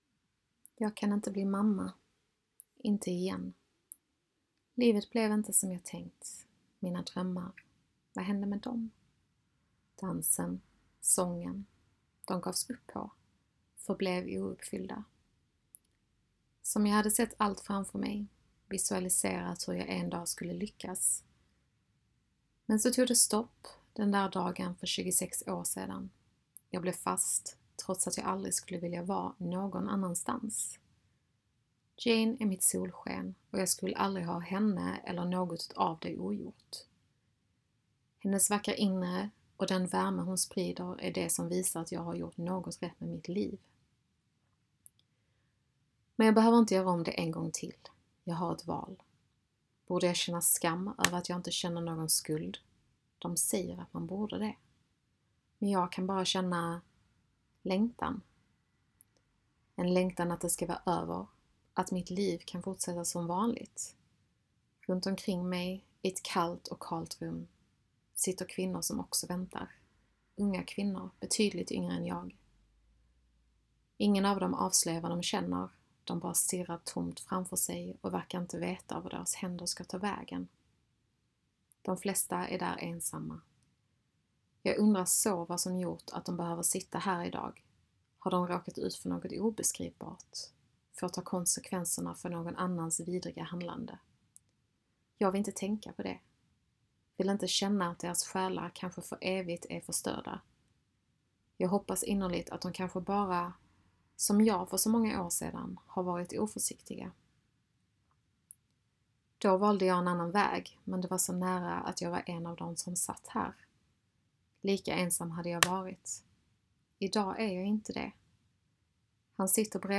Novell och ljudnovell